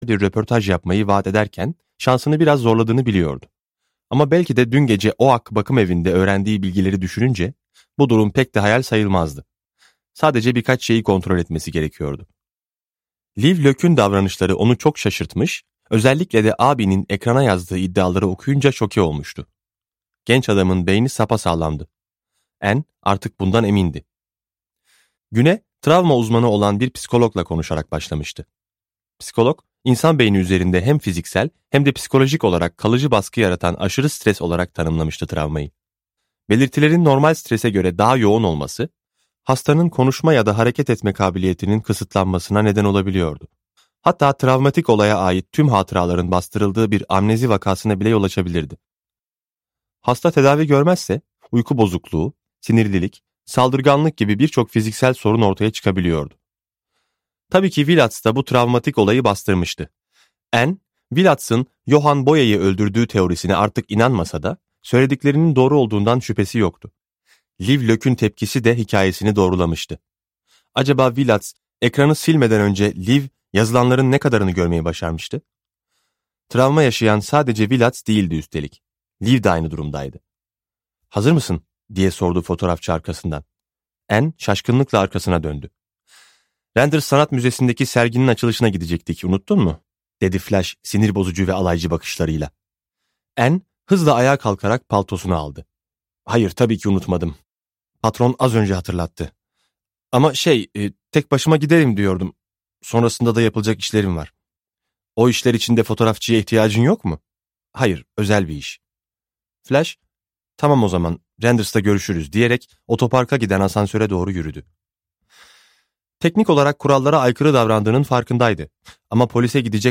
Suçluluk Ateşi - Bölüm 6 - Seslenen Kitap